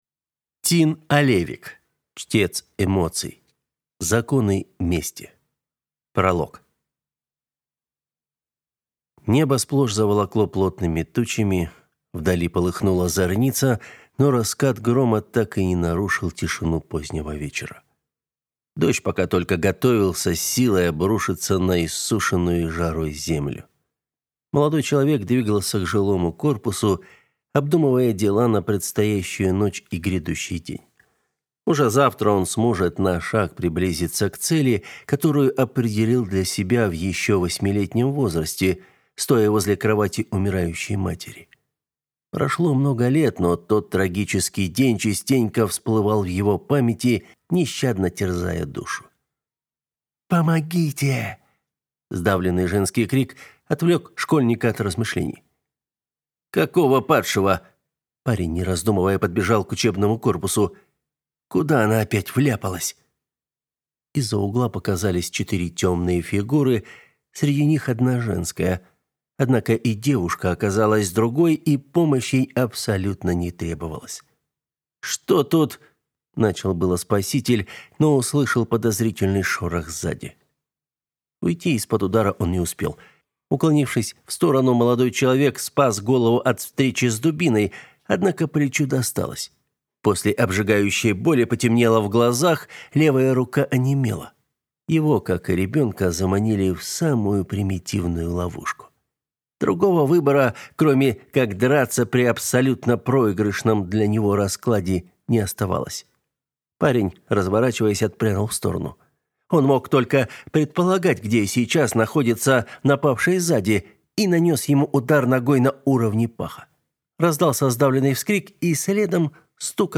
Аудиокнига Чтец эмоций. Законы мести | Библиотека аудиокниг